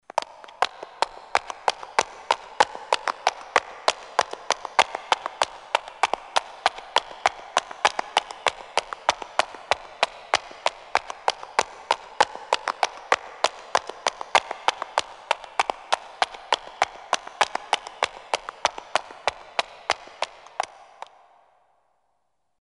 小跑着的高跟鞋在空旷混响强的环境.mp3
通用动作/01人物/01移动状态/高跟鞋/小跑着的高跟鞋在空旷混响强的环境.mp3